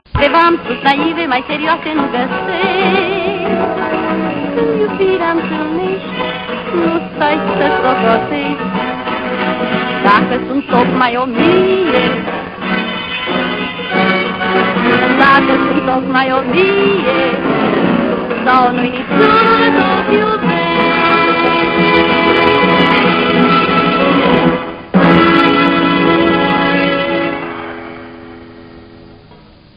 По большей части записи сделаны с КВ-радиостанции Лахти (кроме 13-16) в районе 1960 года (±2-3 года) и оцифрованы.
1. Сохранилась только концовка песни (женский вокал).